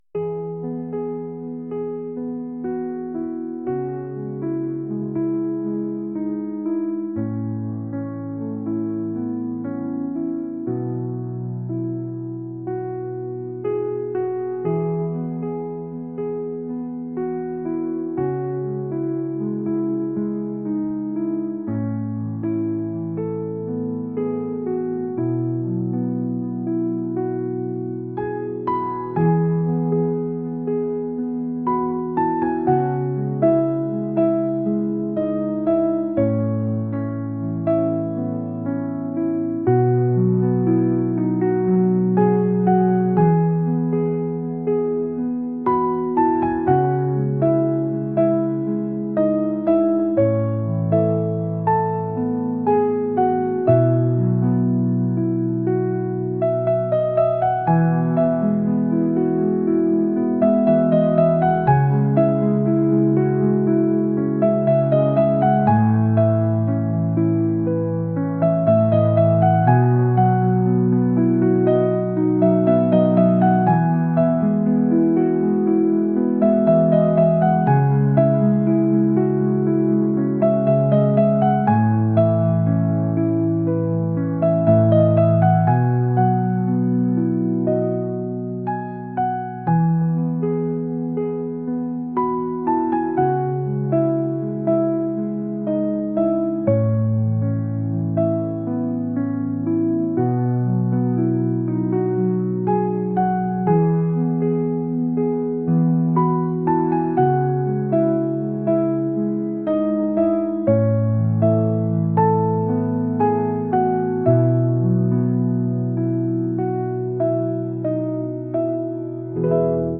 ambient | acoustic | pop